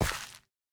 Minecraft Version Minecraft Version 21w07a Latest Release | Latest Snapshot 21w07a / assets / minecraft / sounds / block / rooted_dirt / step4.ogg Compare With Compare With Latest Release | Latest Snapshot
step4.ogg